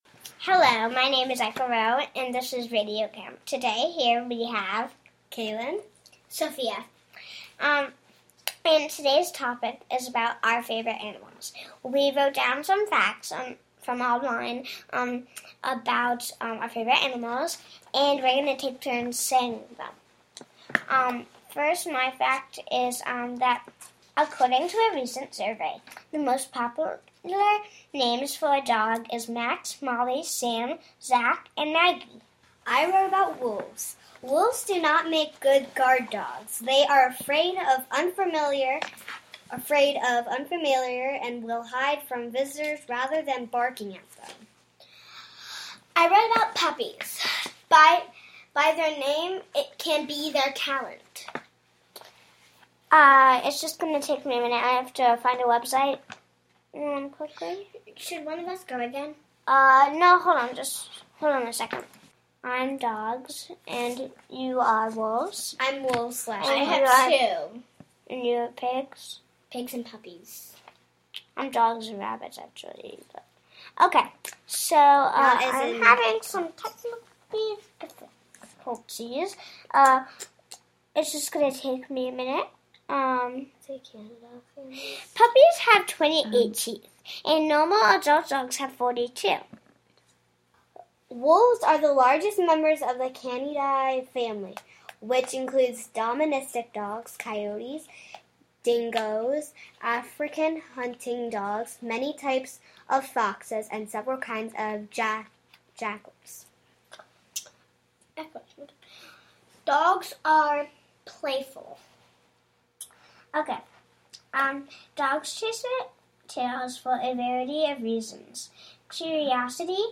Kids radio show about animals.